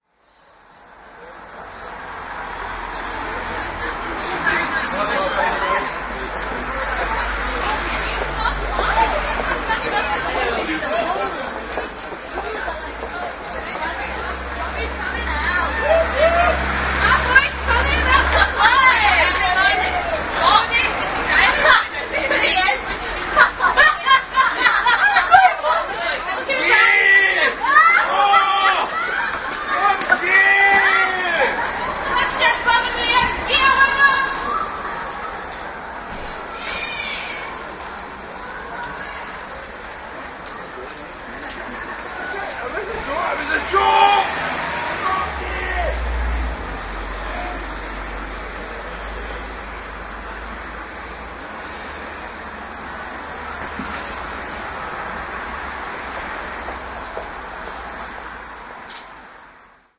描述：格拉斯哥语语音合成的尝试。
Tag: 男性 语音 格拉斯哥 语音合成 苏格兰 苏格兰人